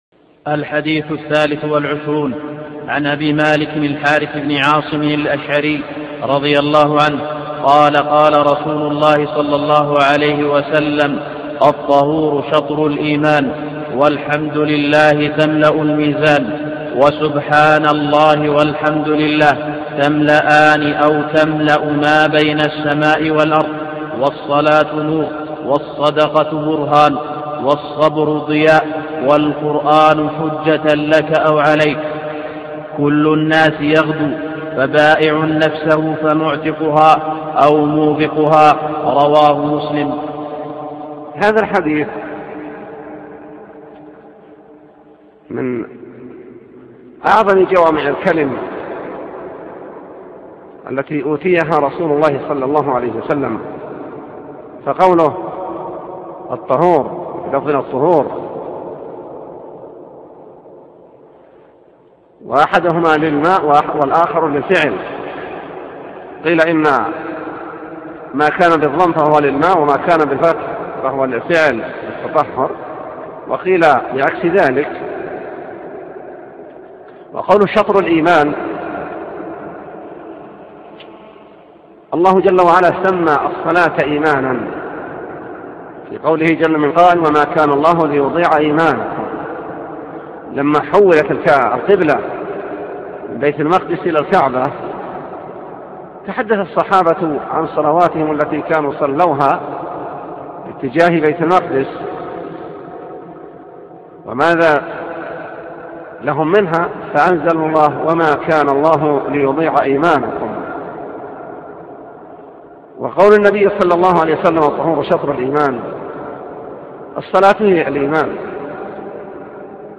23 – شرح حديث الطهور شطر الإيمان – الشيخ : صالح اللحيدان